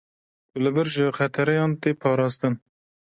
/pɑːɾɑːsˈtɪn/